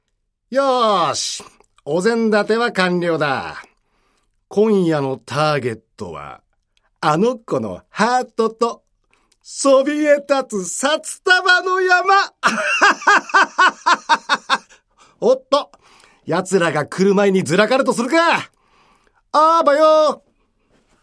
ボイスサンプル
セリフ3